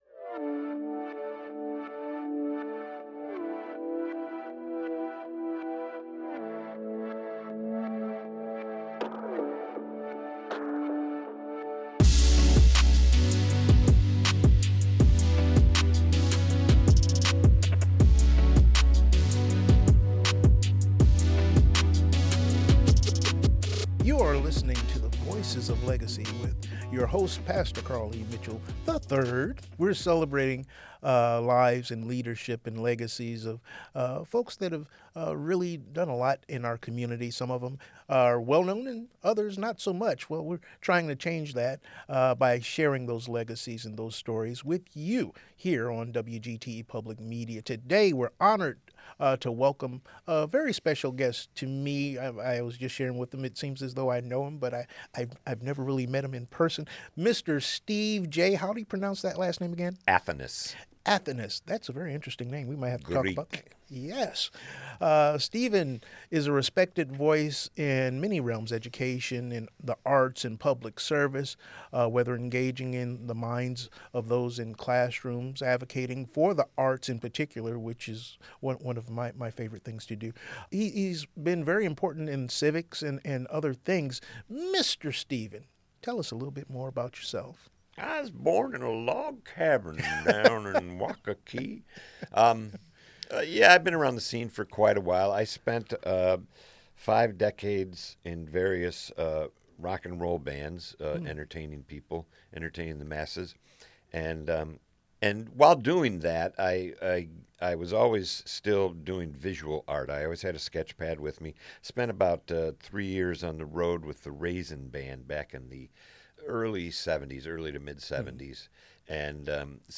The Art of Impact: A Conversation